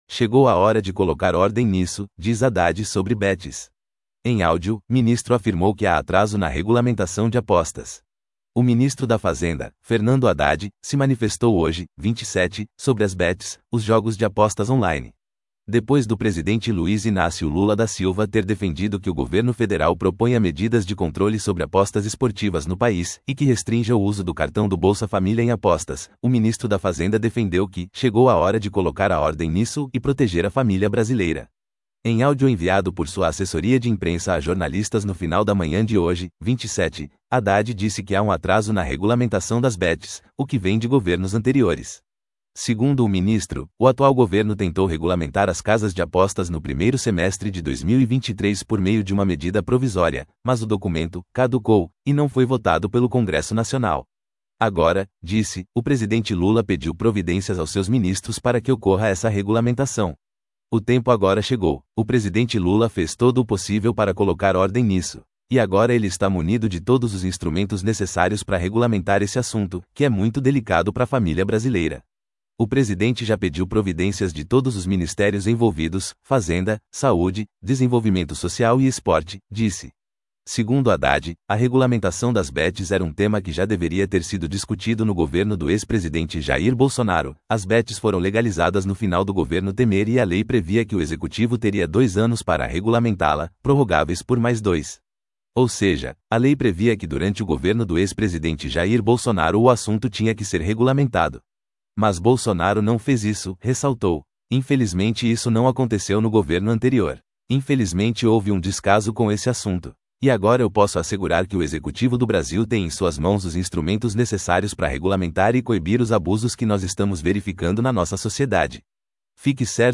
Em áudio, ministro afirmou que há atraso na regulamentação de apostas